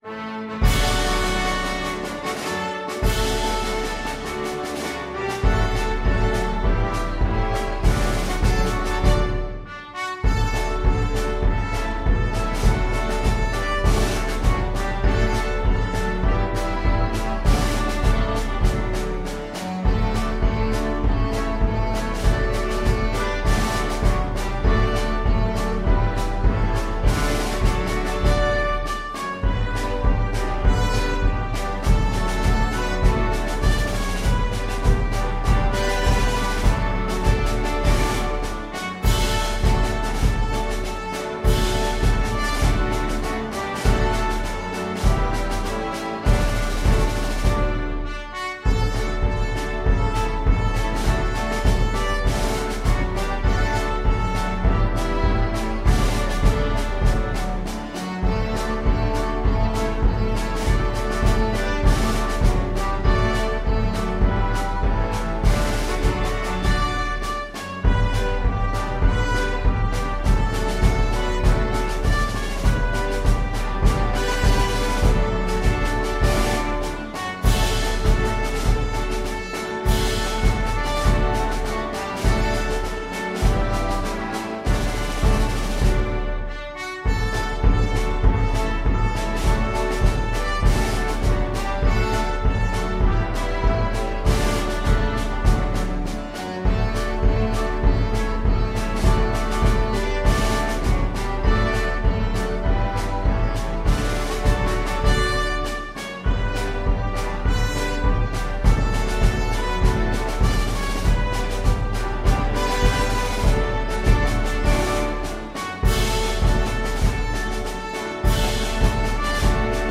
Free Sheet music for Flexible Mixed Ensemble - 5 Players
2/4 (View more 2/4 Music)
March Tempo = c. 100
D major (Sounding Pitch) (View more D major Music for Flexible Mixed Ensemble - 5 Players )
Traditional (View more Traditional Flexible Mixed Ensemble - 5 Players Music)